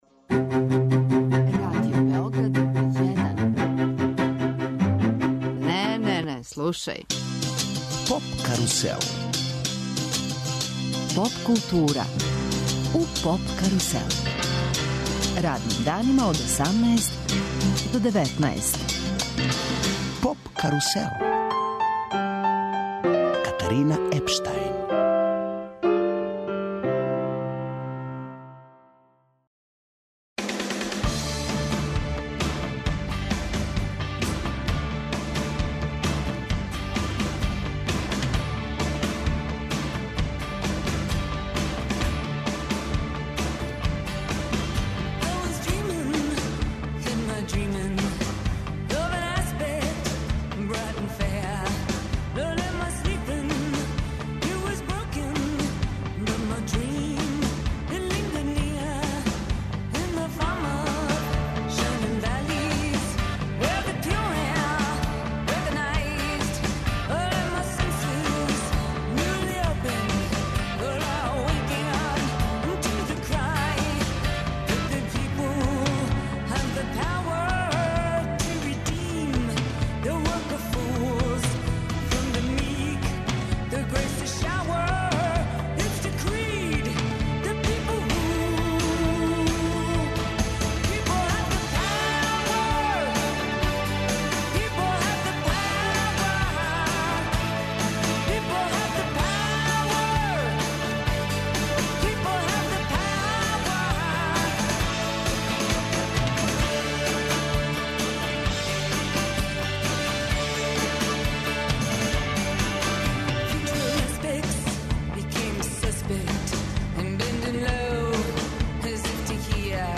"Труба-Игра-Бубањ" тема је овогодишњег Дана бубњара. Наш гост је Драгољуб Ђуричић, прослављени музичар, иницијатор и оснивач овог јединственог музичког догађаја.